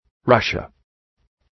{‘rʌʃə}